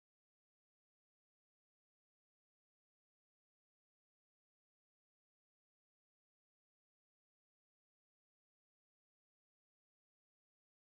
Не могу накрутить pluck.
Я выделил side-составляющую в примере, чтобы услышать его четче. Это то, что подыгрывает вокалу. Похоже на балалайку, но, скорее всего, это pluck с какими-то особыми ADSR на фильтре.